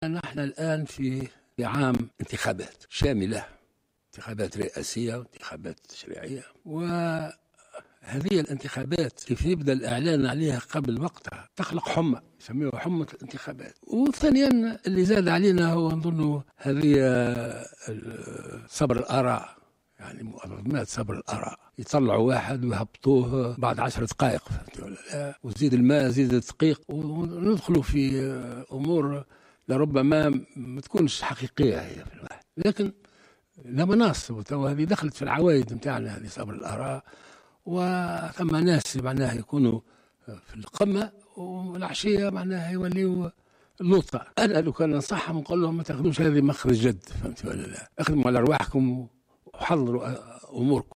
أكد رئيس الجمهورية الباجي قايد السبسي في كلمة توجه بها للشعب التونسي مساء اليوم الأحد بمناسبة دخول شهر رمضان أن الإعلان عن نتائج محتملة للانتخابات قبل أوانها يخلق حمى انتخابية على حد تعبيره.